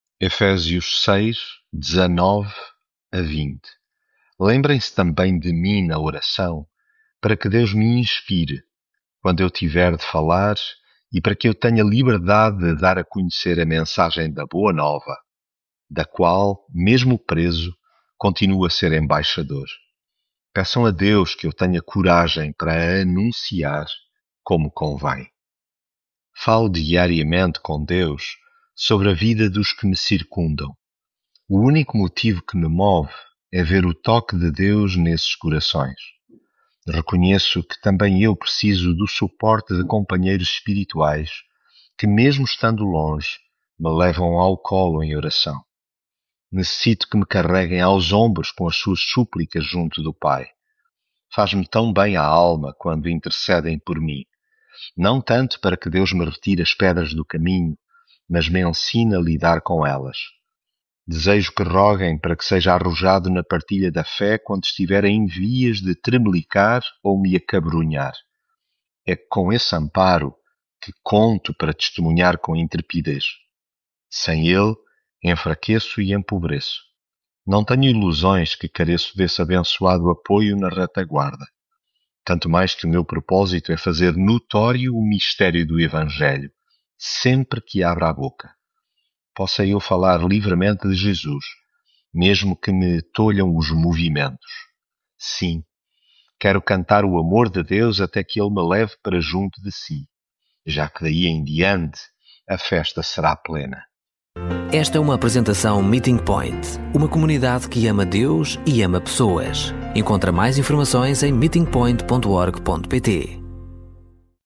devocional Efésios Lembrem-se também de mim na oração, para que Deus me inspire, quando eu tiver de falar, e para que eu tenha liberdade de...